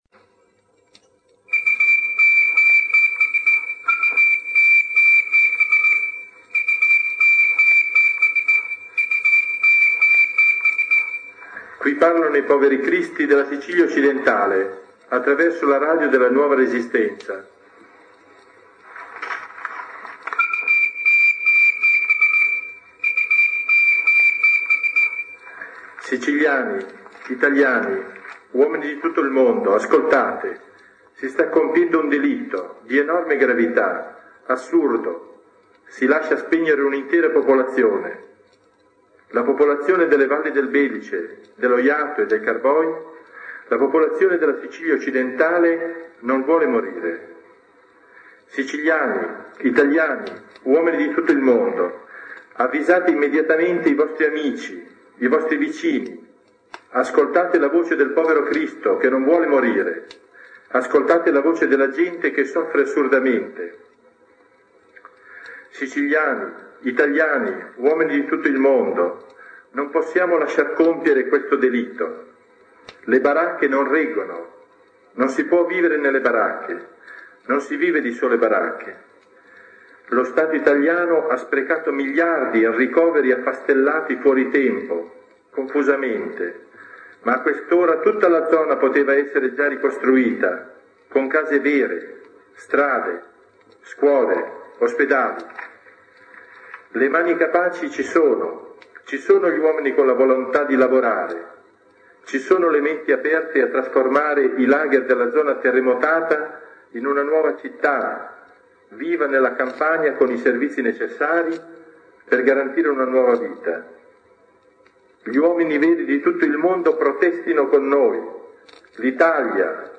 Una voce da Partinico lancia un S.O.S. e un messaggio di denuncia del potere mafioso e clientelare che aveva attinto a piene mani dai soldi destinati alla ricostruzione della valle del Belice, dopo il terremoto del 1968.